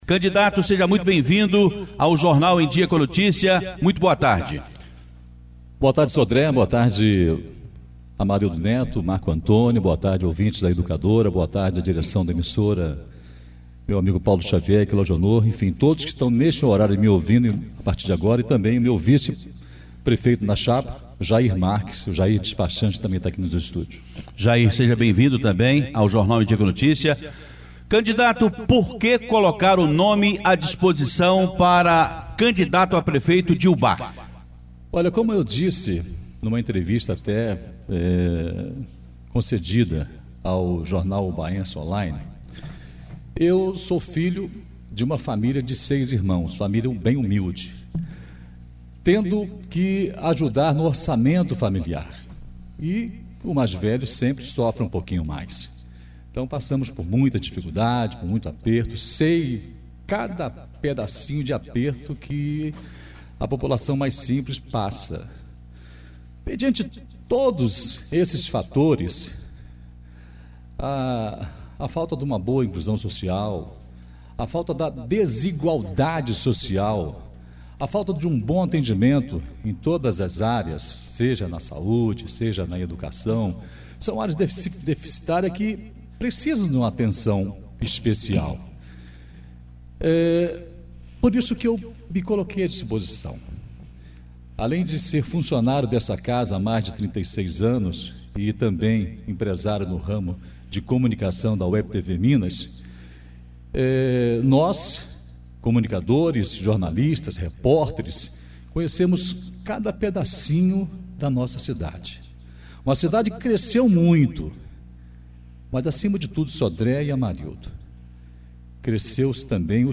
A Rádio Educadora iniciou nesta quarta-feira(14/10) o quadro – EDUCADORA NA BOCA DA URNA – Os candidatos a Prefeito nesta eleição serão entrevistados no Jornal Em dia com a Notícia.
Educadora na boca da urna!Entrevista às 12h30.